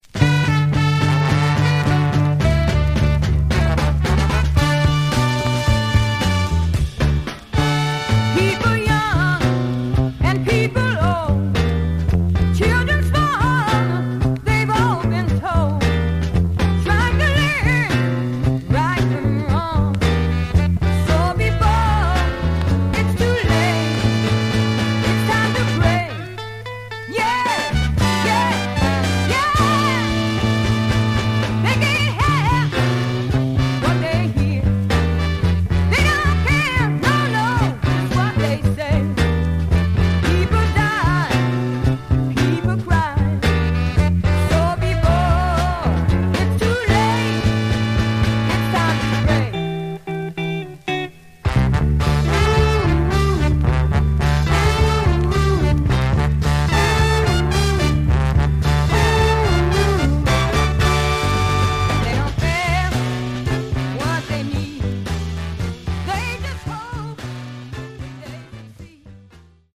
Some surface noise/wear
Mono
Soul